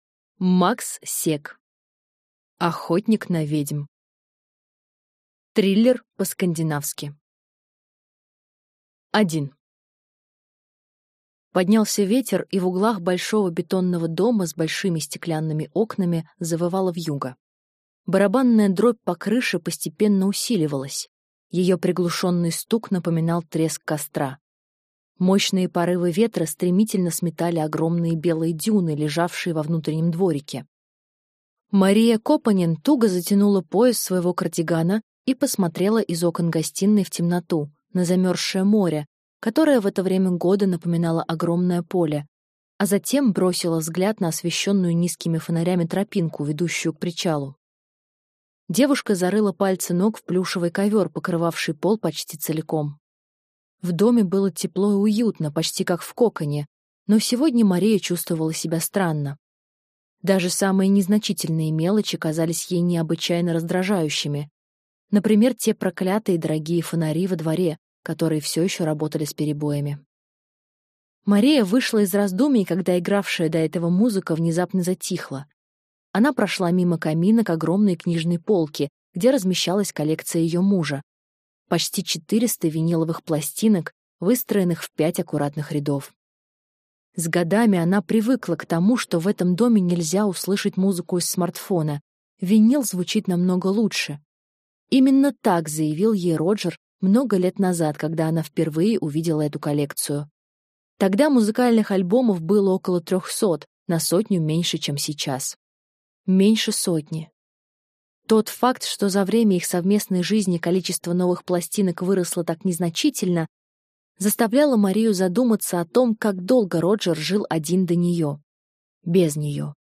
Аудиокнига Охотник на ведьм | Библиотека аудиокниг